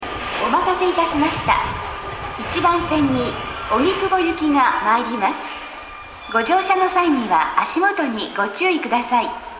スピーカーは天井型が設置されており音質は非常にいいと思います。
新宿、中野坂上、荻窪方面   発車メロディー
接近放送